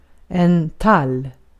Ääntäminen
Synonyymit fura fur pinje pinie Ääntäminen Tuntematon aksentti: IPA: /tal/ Haettu sana löytyi näillä lähdekielillä: ruotsi Käännös Substantiivit 1. pine 2.